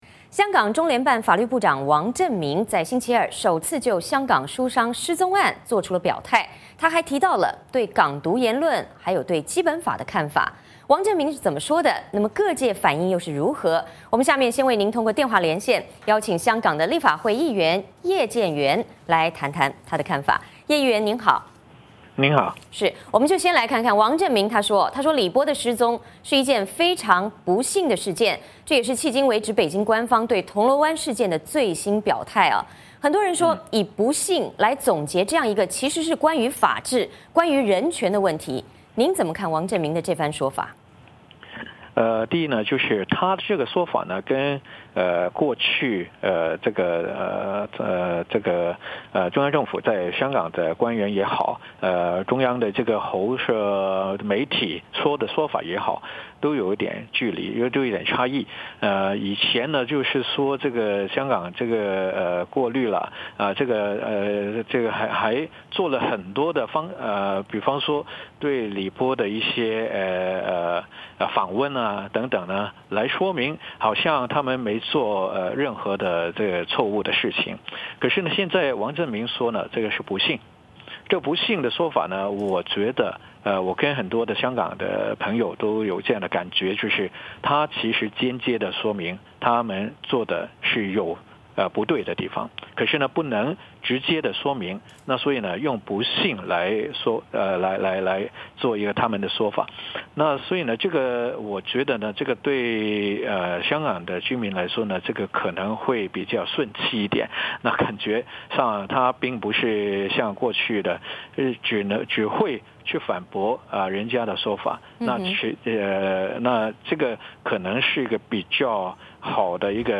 香港中联办法律部长王振民星期二，首次就香港书商失踪案做出表态，还提到对港独言论和基本法的看法，王振民是怎么说的，各界反应如何？我们通过电话连线，请香港立法会议员叶建源来谈谈他的看法。